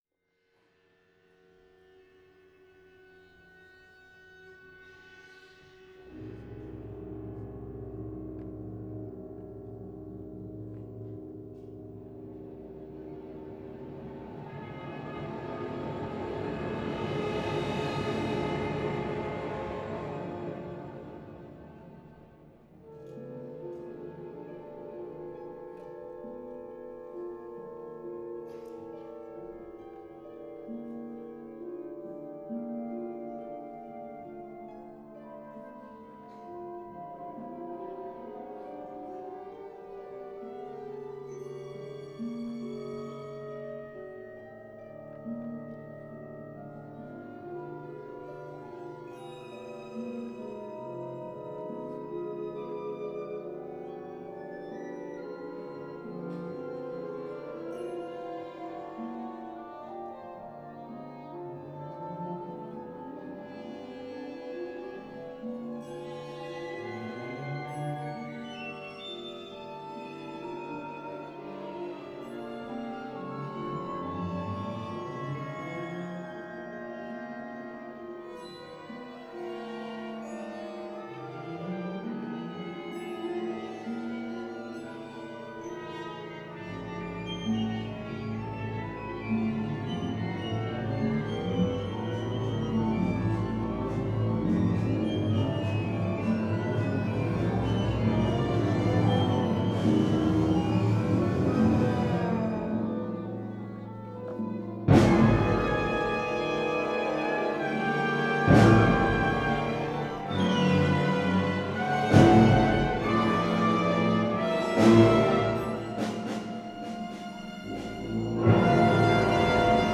for large orchestra